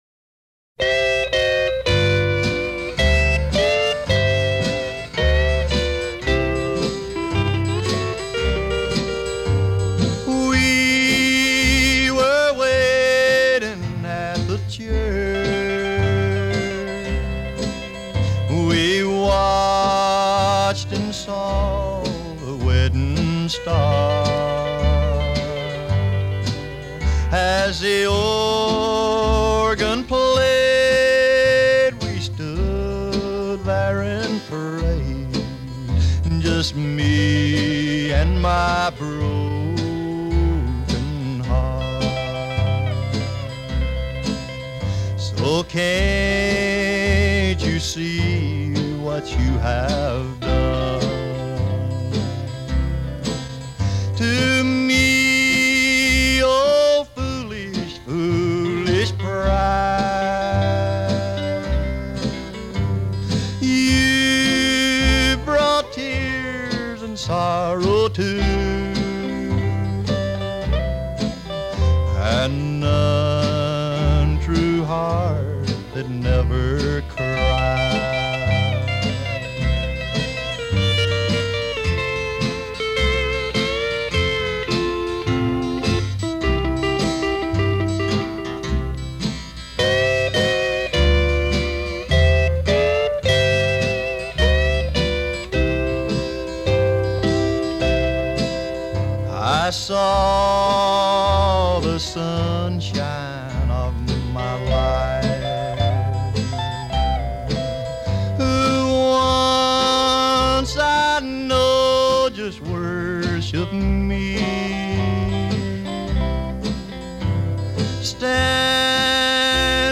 американский кантри-певец.